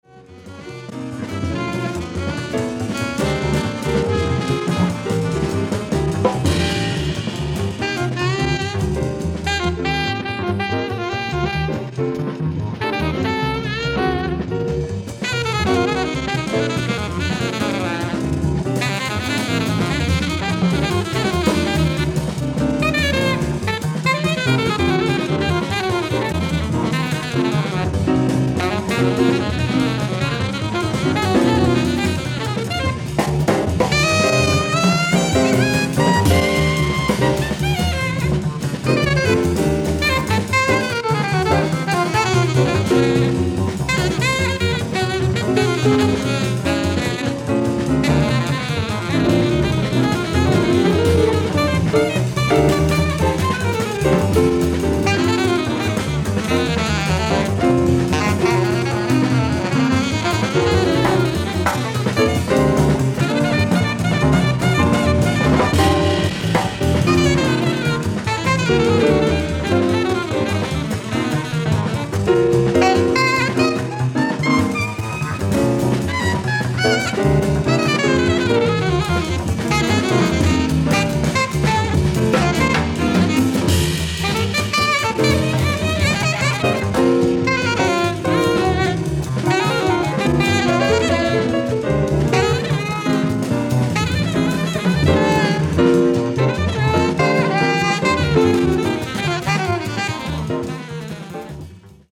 ライブ・アット・カフェ・モンマルトル、コペンハーゲン、デンマーク 10/26/1977
フル・サウンドボード音源！！
※試聴用に実際より音質を落としています。